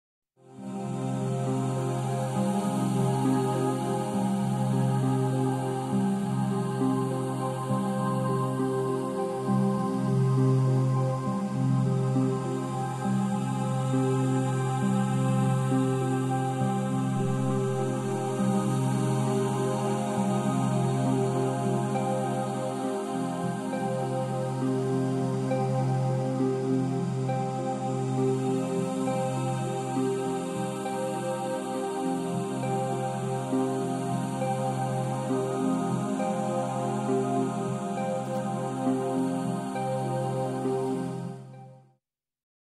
Ambient Electronic Soundscapes
Previously unreleased ambient tracks.